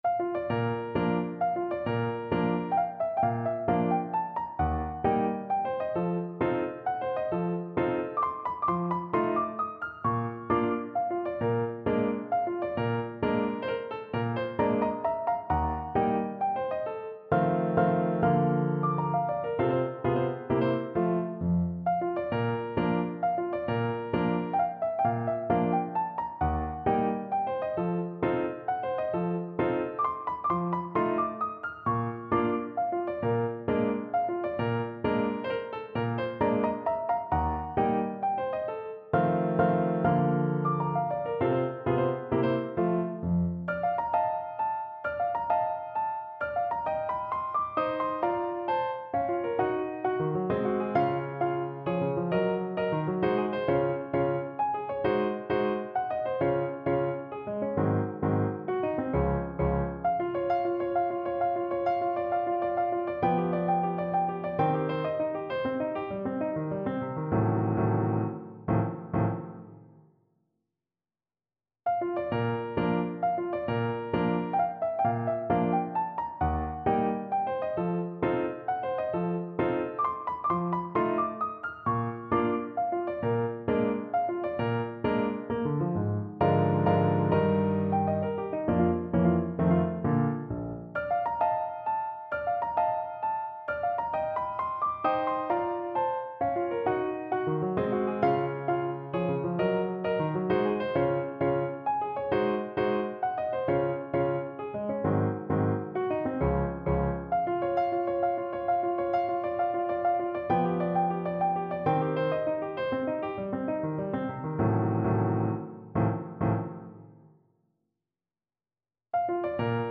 Piano  (View more Intermediate Piano Music)
Classical (View more Classical Piano Music)